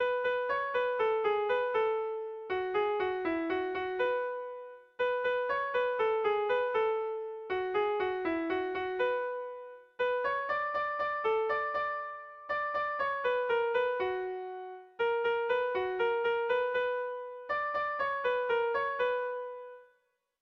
Gabonetakoa
Zortziko ertaina (hg) / Lau puntuko ertaina (ip)
AABD